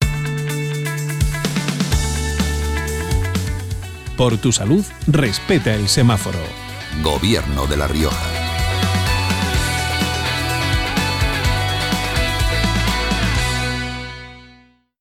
Descargas útiles Informe contrato de servicio de difusión publicitaria Informe contrato de difusión en prensa escrita Resolución Elementos de campaña Cuñas radiofónicas Cuña Medio digital Banner Redes sociales